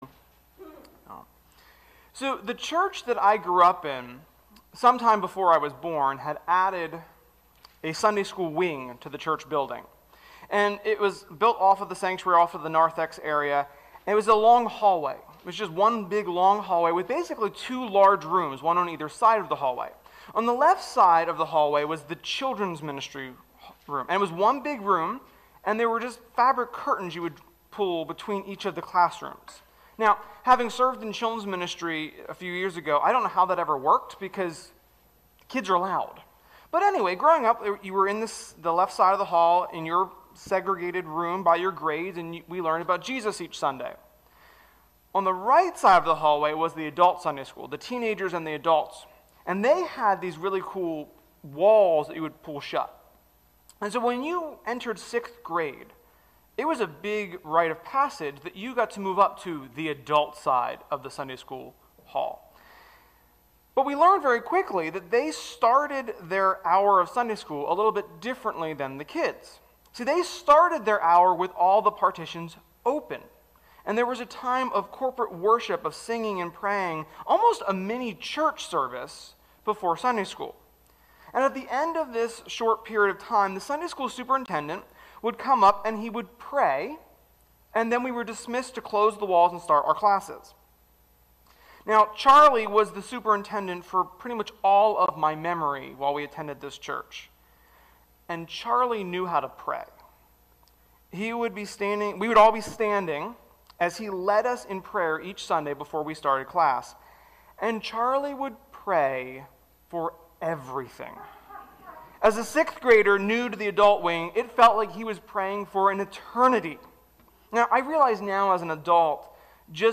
Sermon-2.28.21.mp3